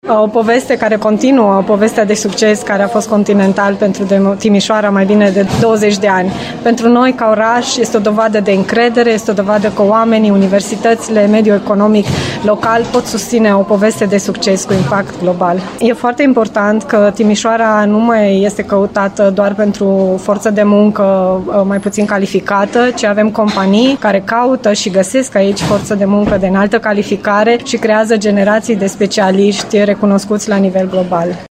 La evenimentul de lansare a noului brand a participat și viceprimarul Timișoarei, Paula Romocean.